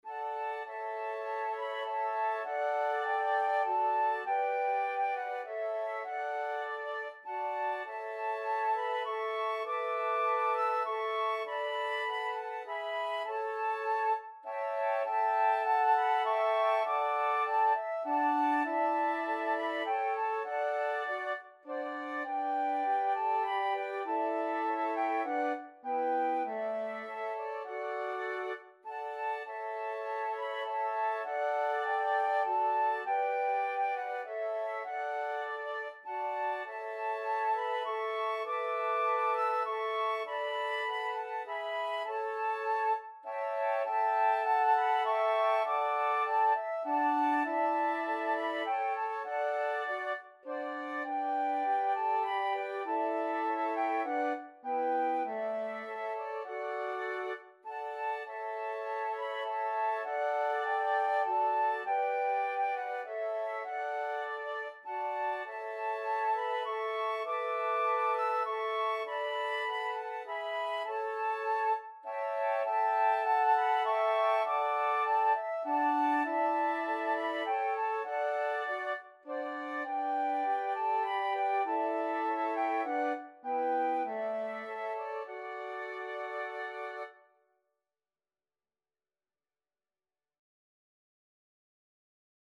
Christian church hymn
flute ensemble